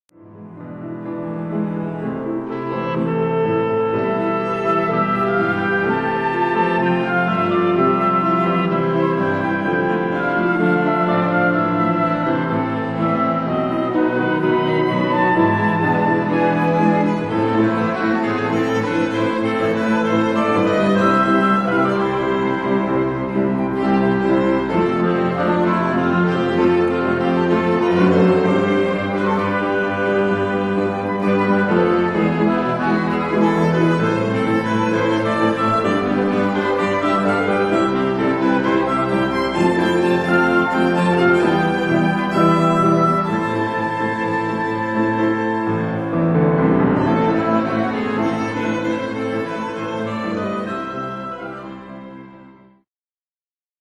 デモ曲につき、全て 1 分前後の抜粋となっています。
音楽ファイルは WMA 32 Kbps モノラルです。
Flute、Oboe、Clarinet、Violin、Cello、Piano